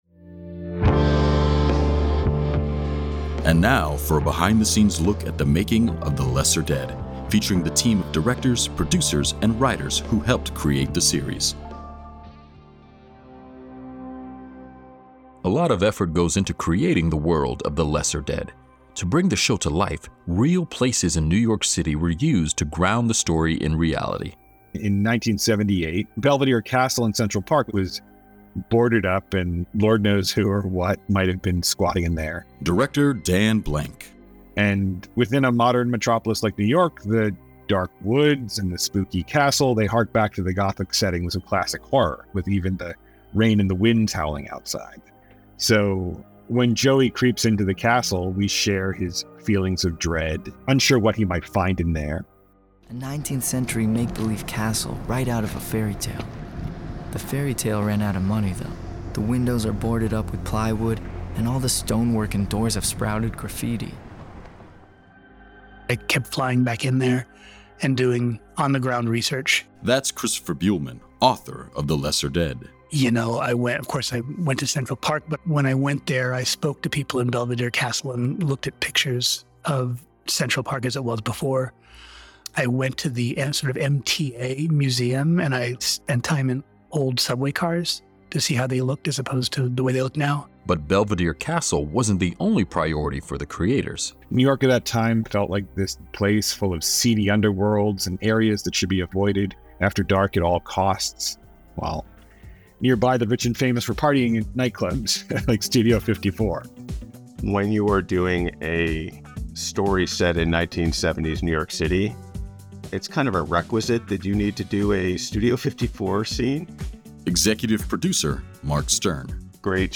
Echoverse will soon be releasing a behind the scenes audio feature on the making of The Lesser Dead, talking to the talented people who brought this story to life.